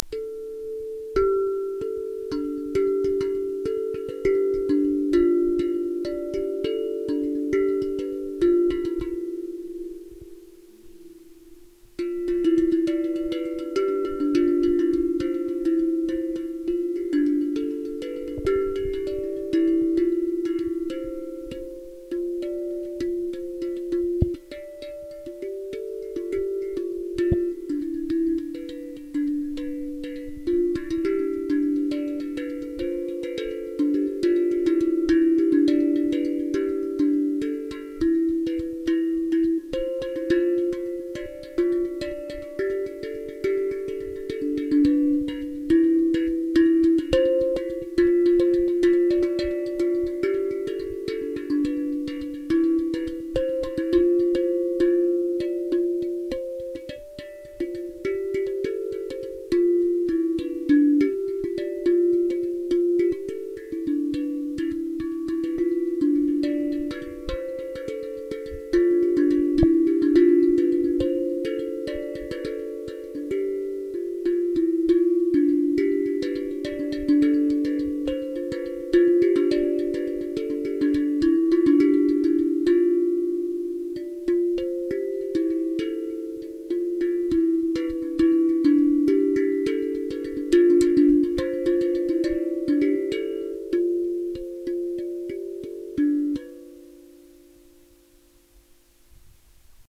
playing on glukofon
Glukofon.ogg